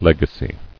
[leg·a·cy]